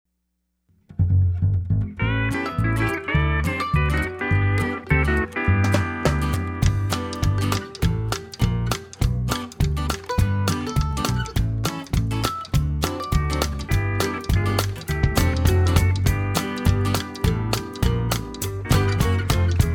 It was fun giving this song a Texas swing flavor
pedal steel
Listen to a sample of this instrumental song.